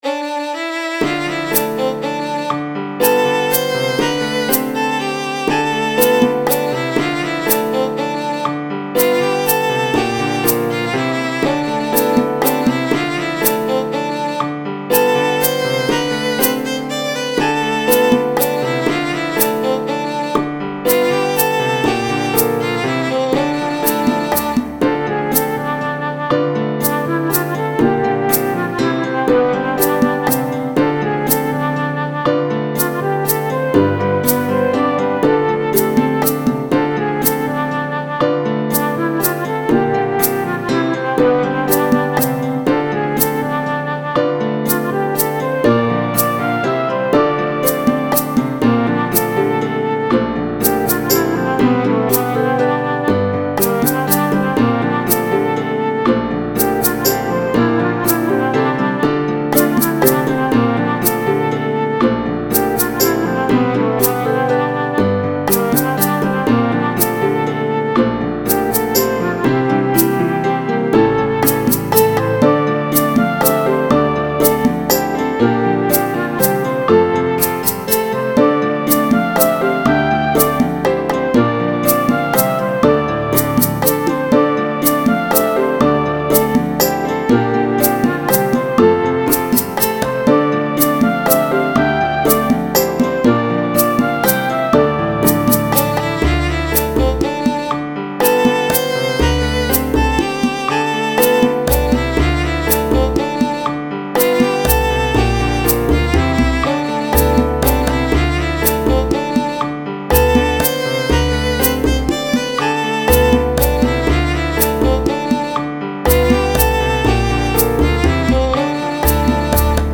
今回のオケのミックスは、あまり音圧を上げない方向にします。
ミックス段階でも、音の粒を揃えるためにコンプレッサーなどをかけるものですが、今回の曲はアコースティックなライブ形式なので強く音を潰すことはせずにダイナミクス（音の強弱）を大事にしていきます。
仮メロディで使ったフルートでも良い感じに聴こえるので、インストゥルメンタル楽曲として、